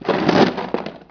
1 channel
ammoBox_pickup.wav